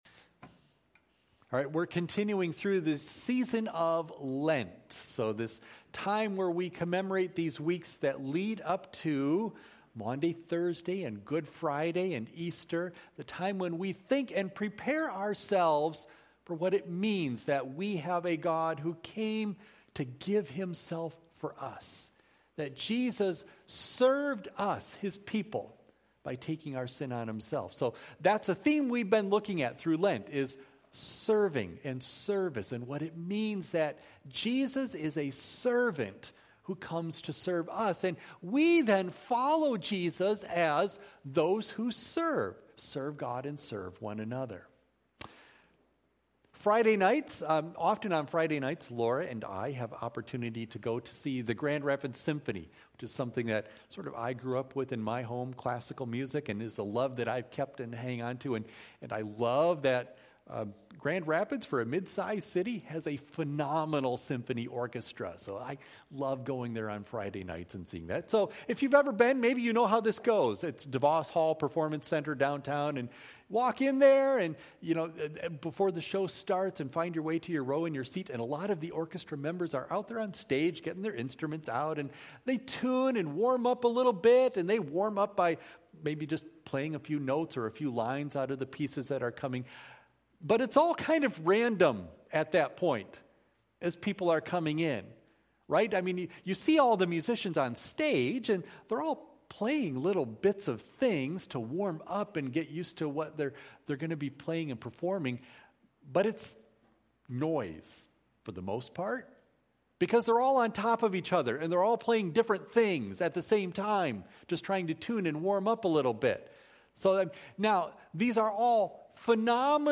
Audio of Message